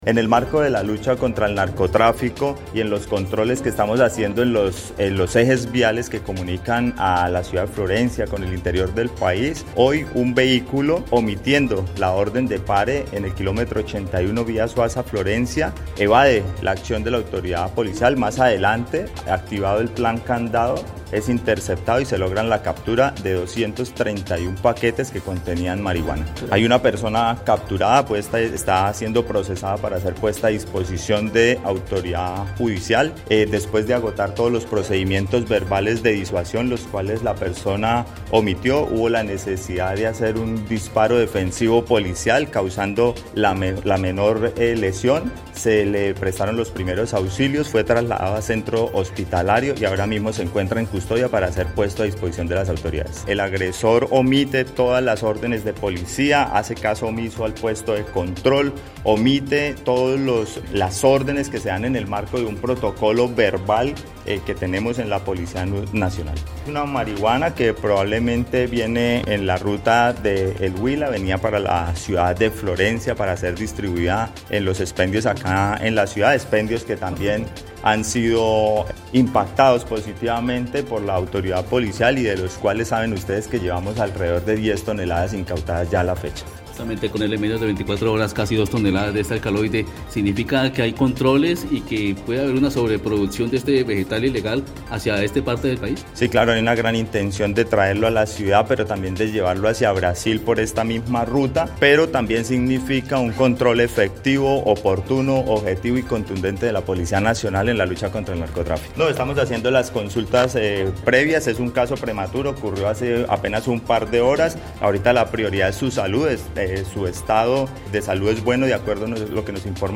El coronel César Pinzón Higuera, comandante de la Policía Caquetá, explicó que, el conductor evadió el llamado de los uniformados en un puesto de control lo cual generó la respuesta de sus hombres terminando en la incautación del alcaloide y la captura del conductor.
03_CORONEL_CESAR_PINZON_MARIMBALALA.mp3